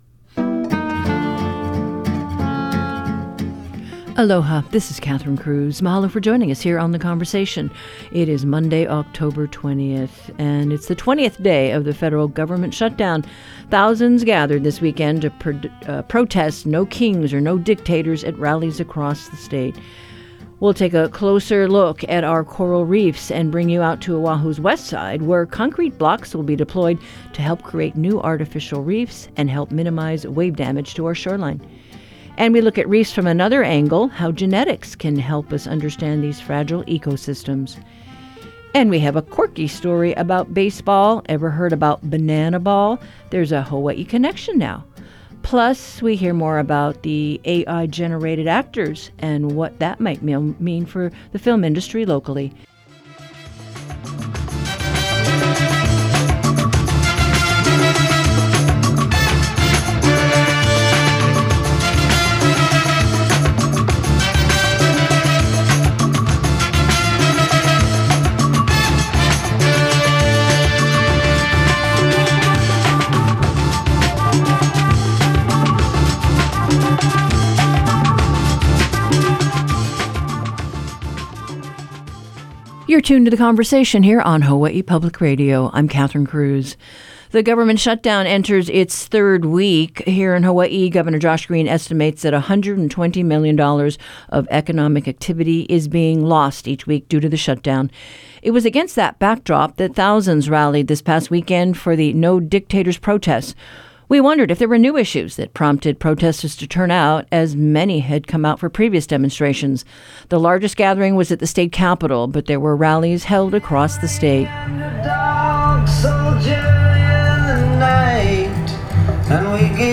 HPR speaks to protestors at Honolulu's 'No Kings' rally